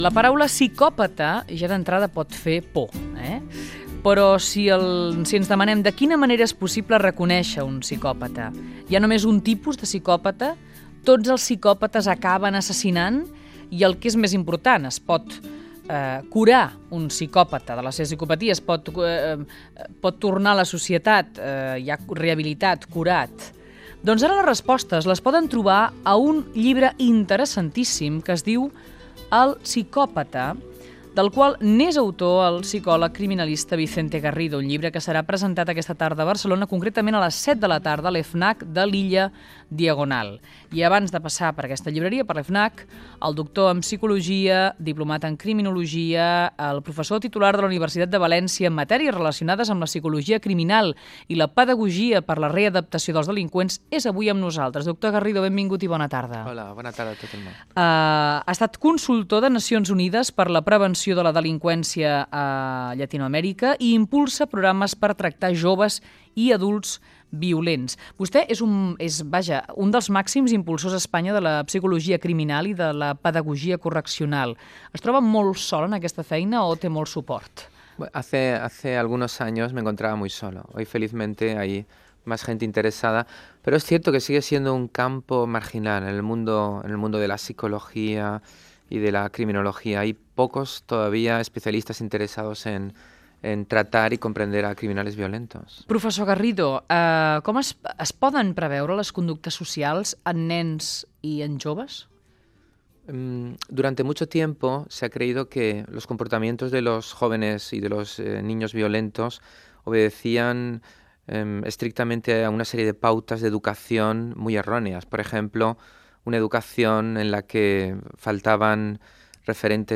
Fragment d'una entrevista al doctor en psicologia i criminilogia Vicente Garrido, autor del llibre "El psicópata".